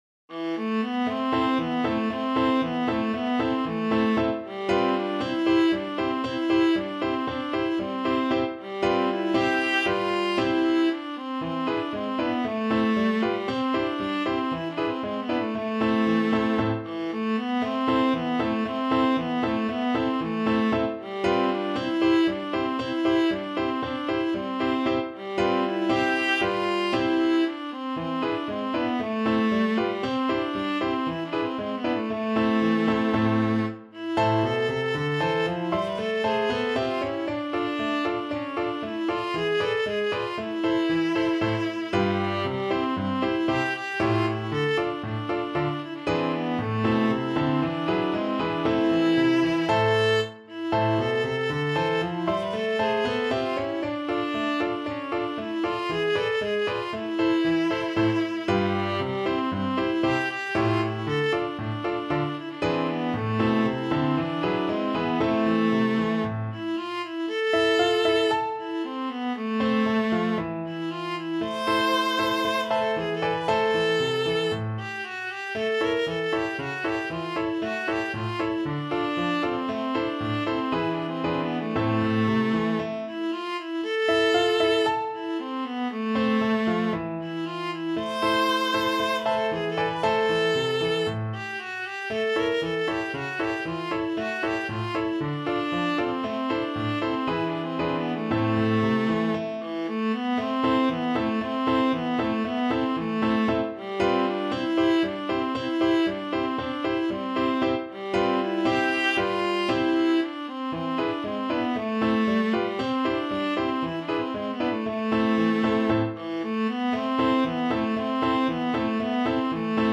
Allegro =c.116 (View more music marked Allegro)
2/4 (View more 2/4 Music)
world (View more world Viola Music)